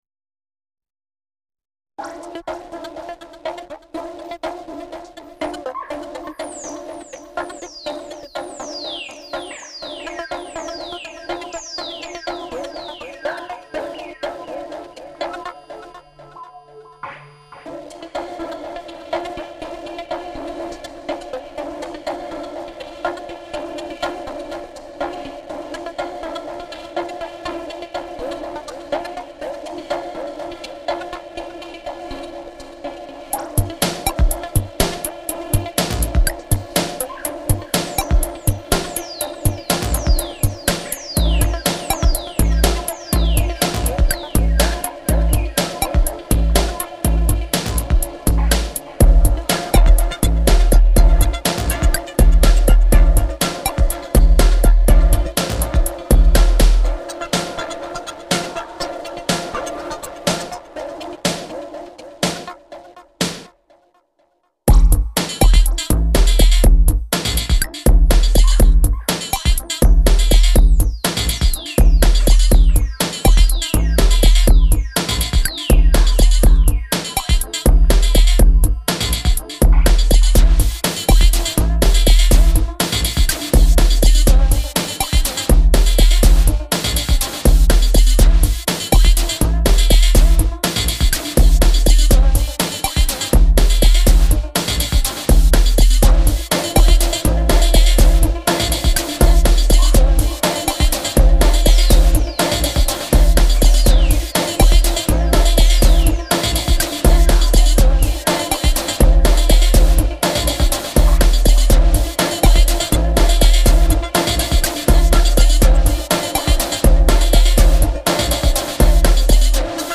die vocal schnipsel sind geil die drumsch uumb
wenns dann mal rollt rockt es ungemein .
nennt man das noch bastard pop? [Wink]